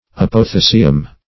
Search Result for " apothecium" : Wordnet 3.0 NOUN (1) 1. a cuplike ascocarp in many lichens and ascomycetous fungi ; The Collaborative International Dictionary of English v.0.48: Apothecium \Apo`*the"ci*um\, n.; pl.